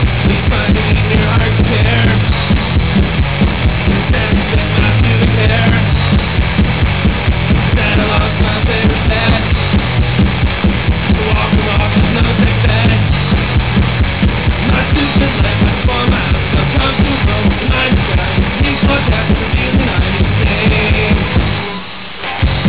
We did it at Sound Dimensions in Timmins.
All the samples are .wav, 8KHz, 8-bit